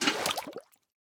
Minecraft Version Minecraft Version 1.21.4 Latest Release | Latest Snapshot 1.21.4 / assets / minecraft / sounds / item / bucket / fill_axolotl1.ogg Compare With Compare With Latest Release | Latest Snapshot
fill_axolotl1.ogg